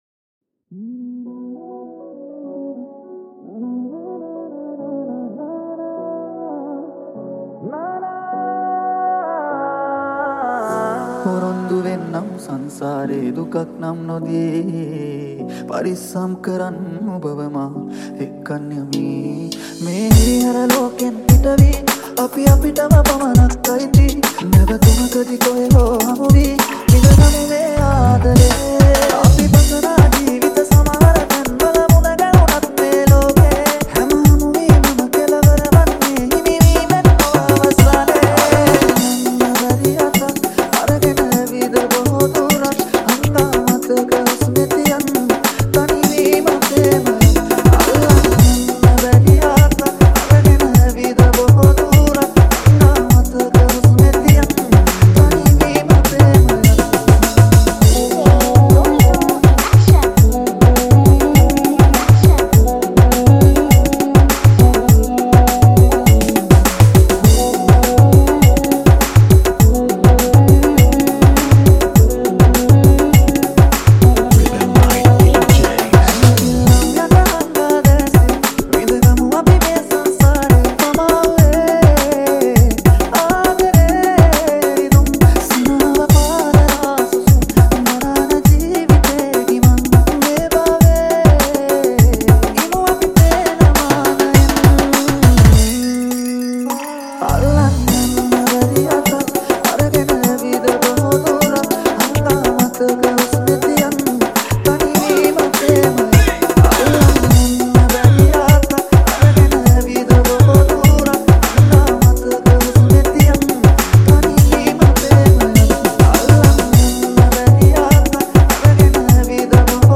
High quality Sri Lankan remix MP3 (5.3).